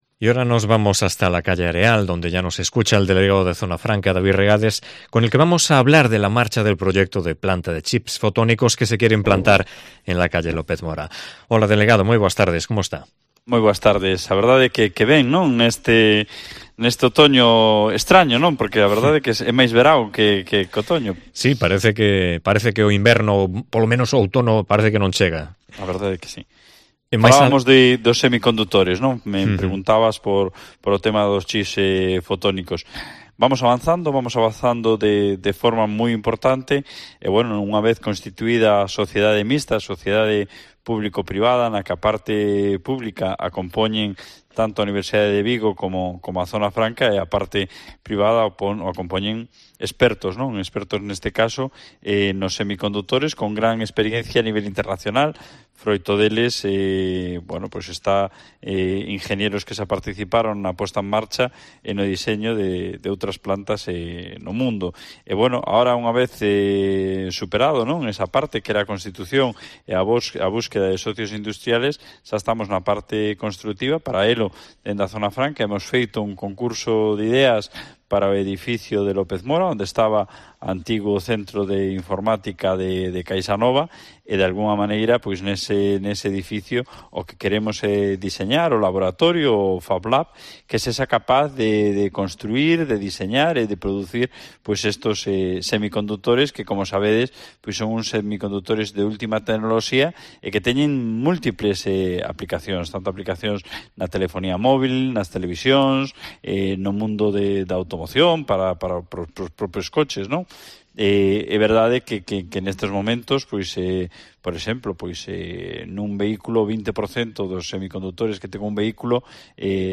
Entrevista con el delegado de Zona Franca, David Regades